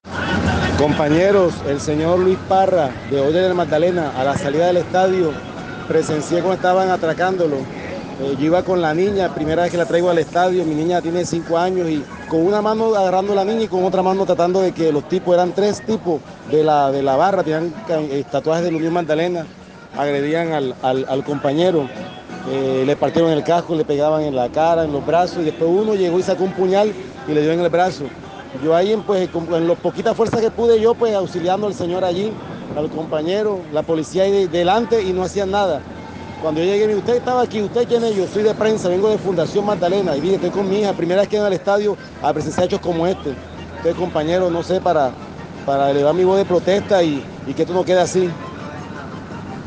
Testigo del atraco a reportero gráfico a la salida del Sierra Nevada